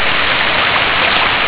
hose.wav